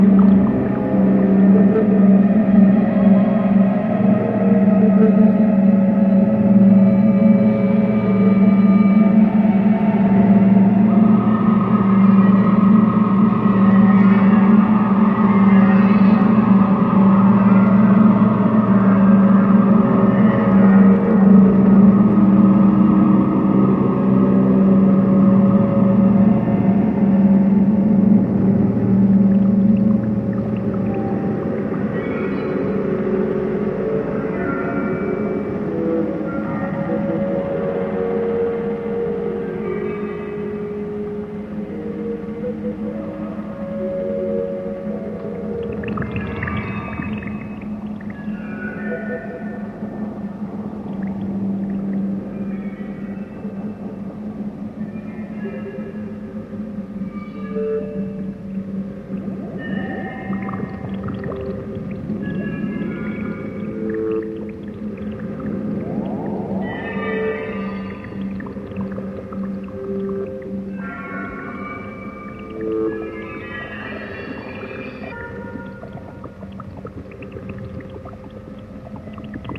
ROCK / 70'S / GERMAN ROCK / PROGRESSIVE ROCK / COSMIC
ルーツ・オブ・テクノ/コズミックなジャーマン・ロック！